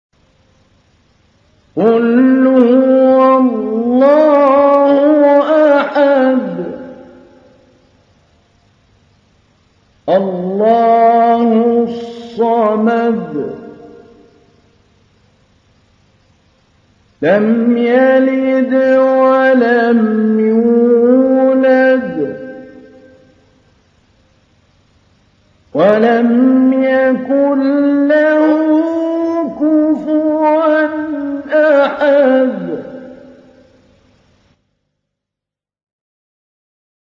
سورة الإخلاص | القارئ محمود علي البنا